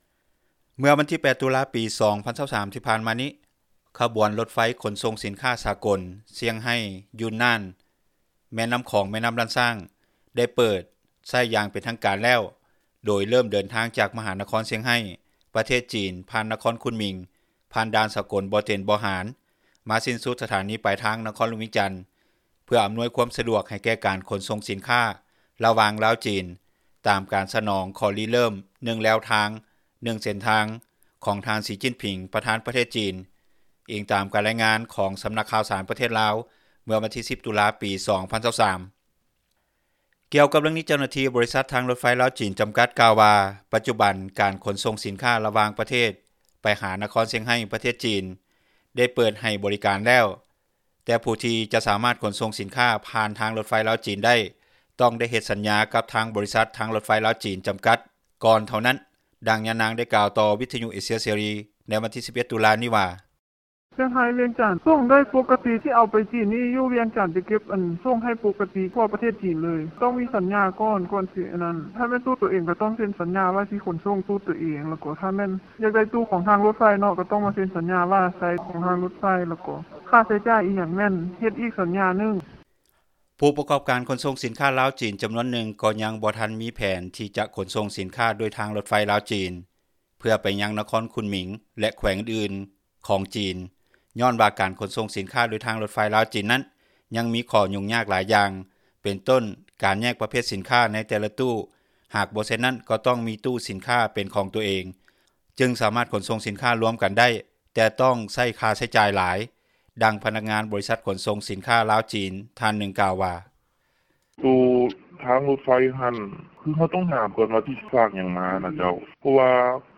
ດັ່ງຍານາງກ່າວ ຕໍ່ວິທຍຸເອເຊັຽ ເສຣີ ໃນວັນທີ 11 ຕຸລາ ນີ້ວ່າ:
ດັ່ງພະນັກງານ ບໍຣິສັດຂົນສົ່ງສິນຄ້າ ລາວ-ຈີນ ທ່ານນຶ່ງກ່າວວ່າ: